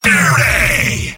Robot-filtered lines from MvM. This is an audio clip from the game Team Fortress 2 .
{{AudioTF2}} Category:Sniper Robot audio responses You cannot overwrite this file.
Sniper_mvm_cheers07.mp3